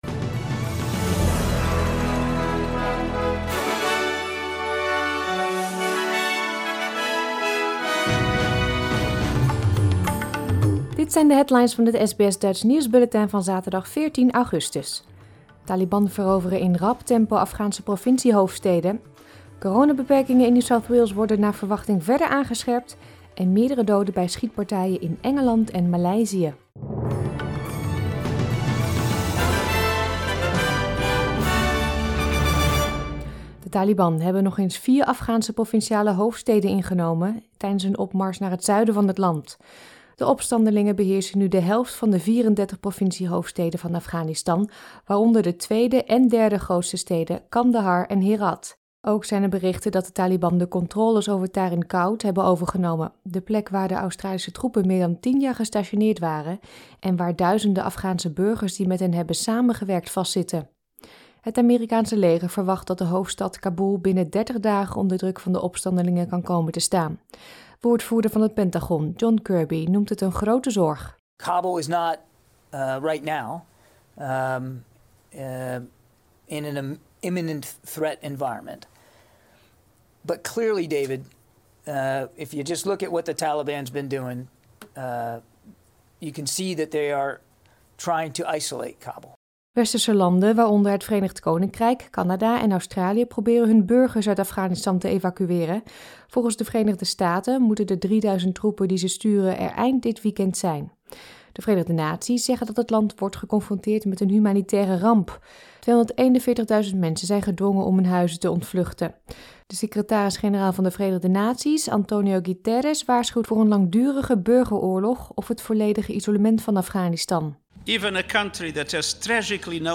Nederlands/Australisch SBS Dutch nieuwsbulletin van zaterdag 14 augustus 2021